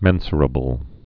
(mĕnsər-ə-bəl, -shər-)